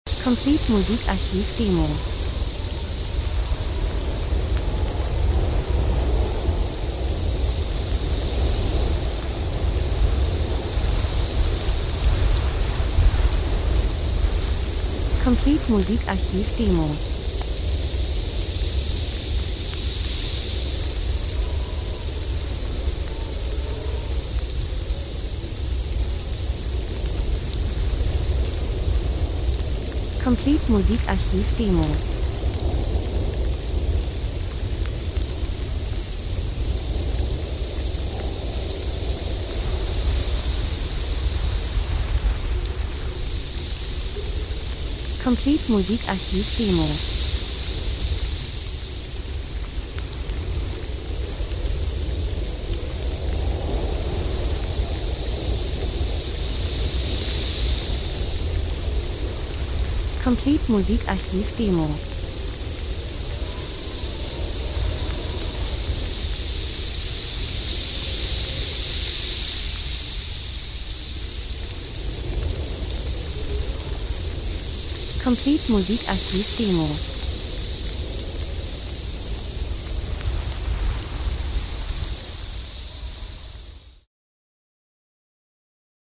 Winter -Sturm Wind Regen Niederschlag kalt 01:26